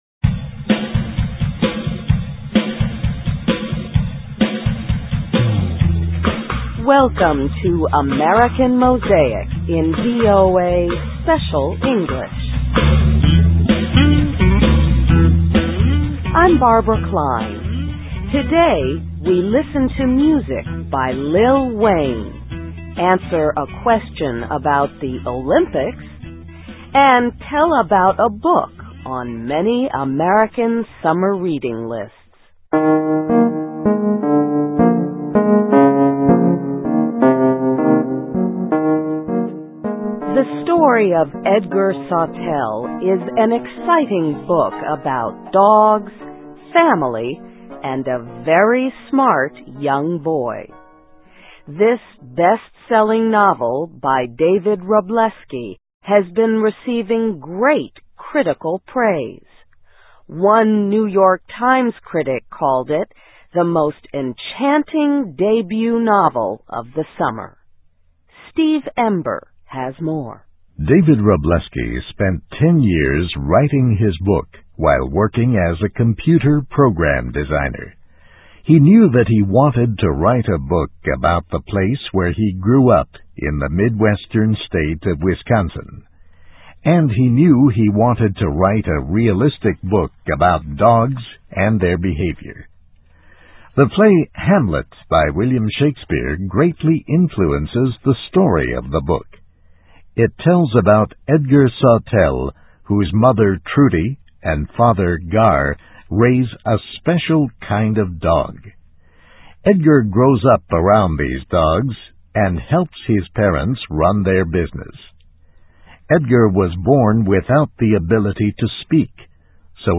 Listen and Read Along - Text with Audio - For ESL Students - For Learning English
Welcome to AMERICAN MOSAIC in VOA Special English.